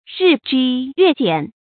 日朘月減 注音： ㄖㄧˋ ㄐㄨㄢ ㄩㄝˋ ㄐㄧㄢˇ 讀音讀法： 意思解釋： 見「日削月朘」。